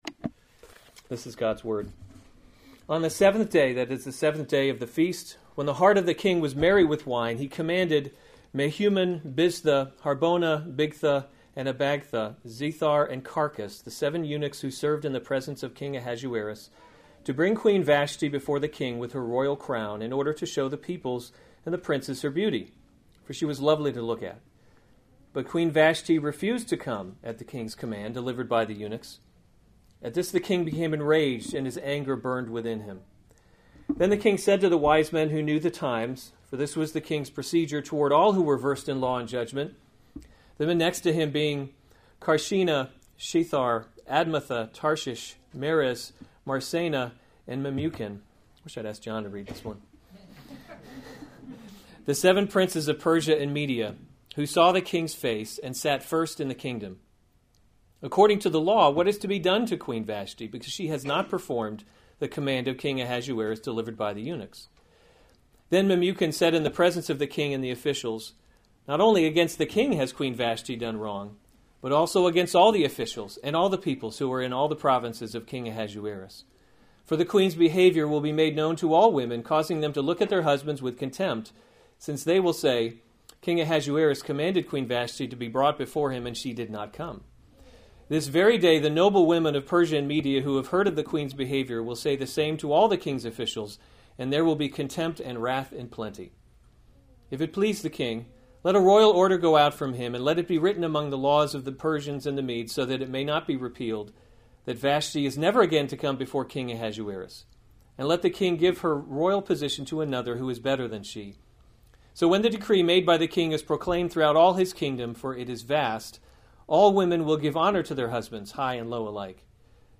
September 17, 2016 Esther: God’s Invisible Hand series Weekly Sunday Service Save/Download this sermon Esther 1:10-22 Other sermons from Esther Queen Vashti’s Refusal 10 On the seventh day, when the heart […]